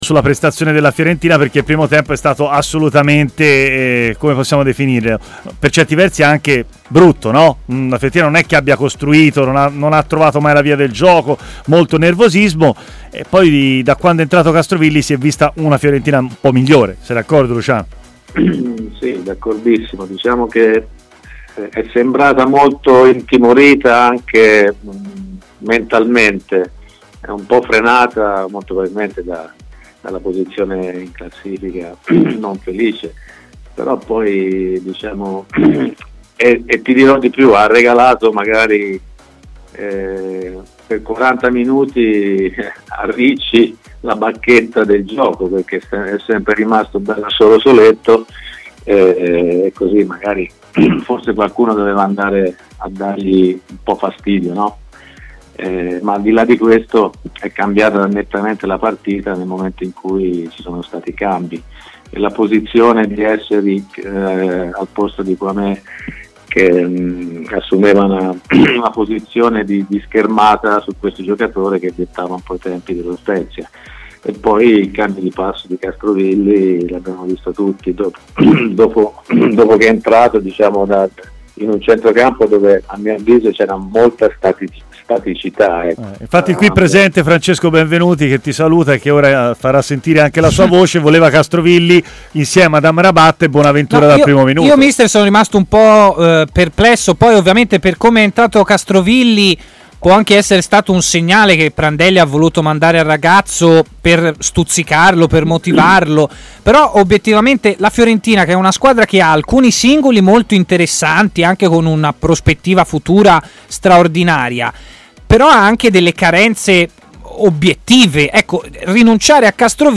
è intervenuto in diretta a Stadio Aperto, su TMW Radio, dopo la partita tra Fiorentina e Spezia